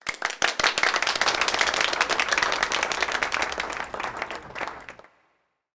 Applause Sound Effect
Download a high-quality applause sound effect.
applause-5.mp3